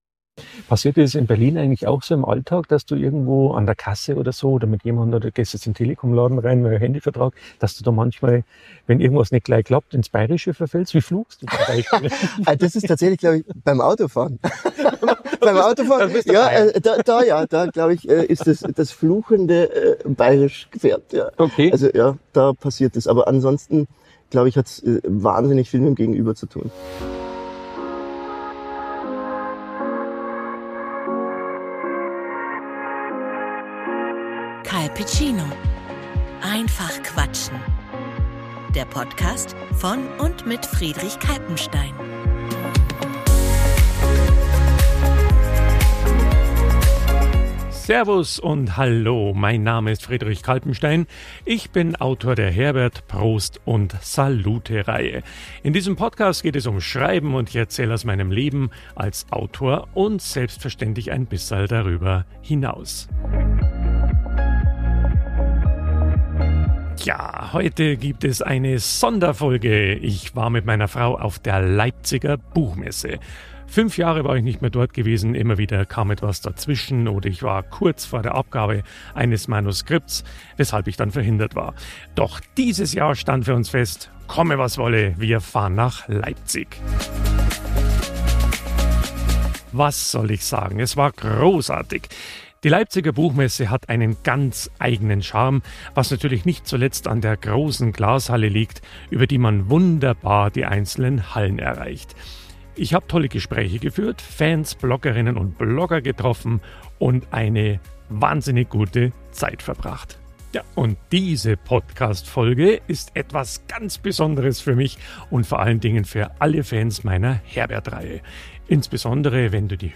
Es geht um echte Begegnungen, lustige Anekdoten aus dem Autorenalltag und den besonderen Zauber, wenn Stimme und Text aufeinandertreffen. Du hörst, wie Messeatmosphäre klingt und was passiert, wenn Bayern sich in Berlin wiederfinden.